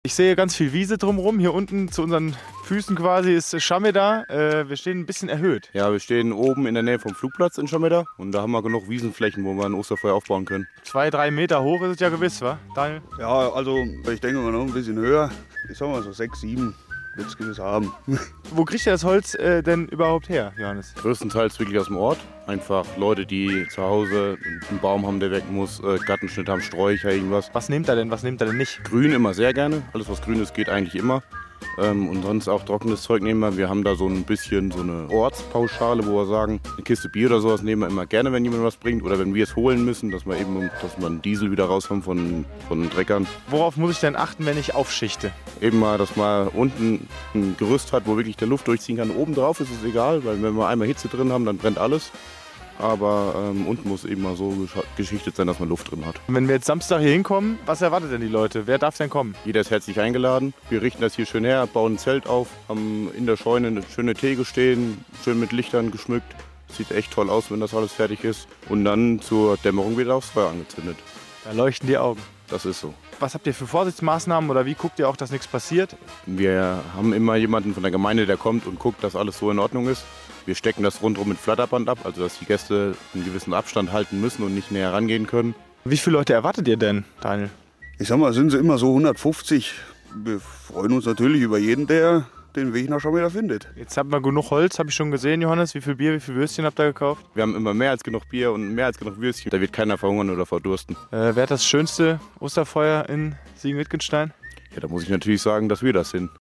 Osterfeufer Schameder Interview